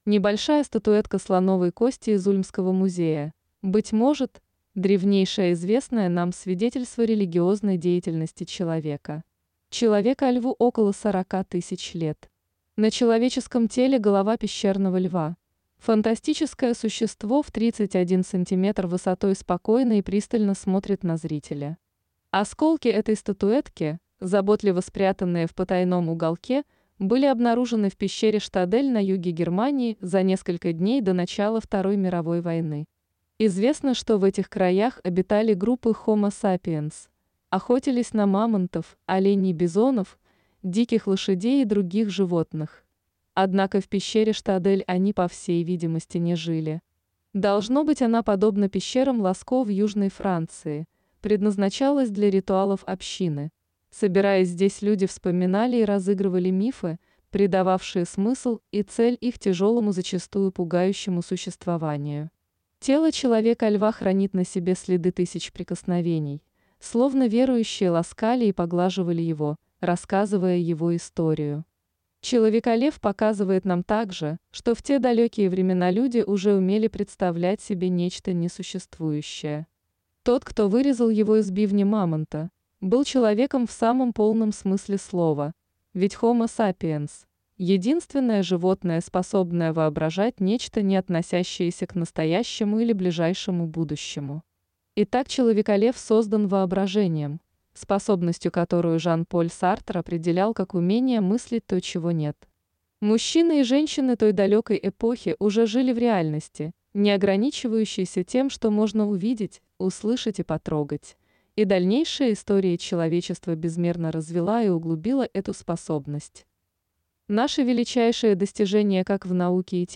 Аудиокнига Утраченные смыслы сакральных текстов. Библия, Коран, Веды, Пураны, Талмуд, Каббала | Библиотека аудиокниг